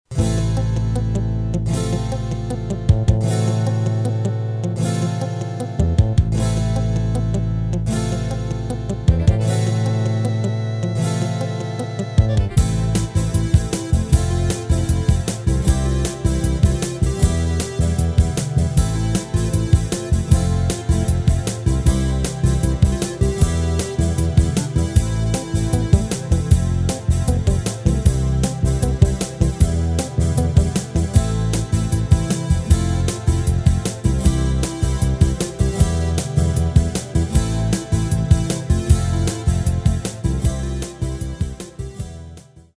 Rubrika: Folk, Country
Karaoke
HUDEBNÍ PODKLADY V AUDIO A VIDEO SOUBORECH